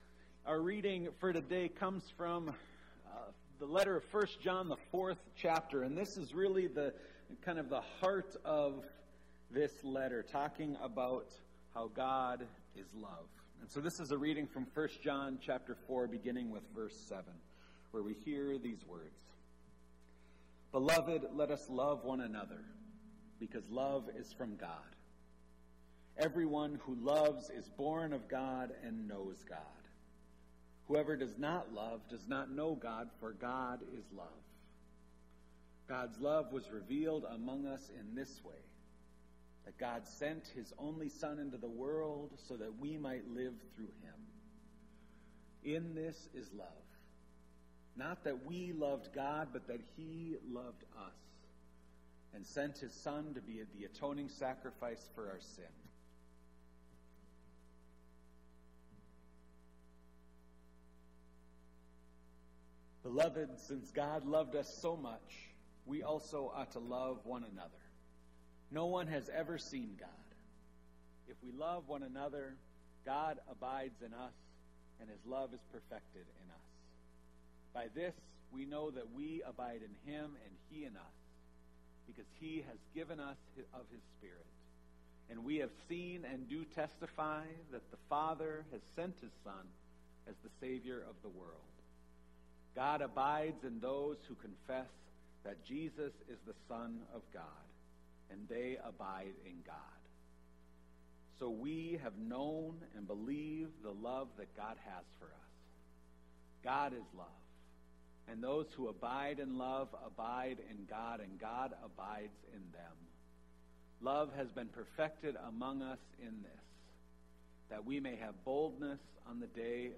Street sermon in Loveland.